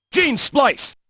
Unused voices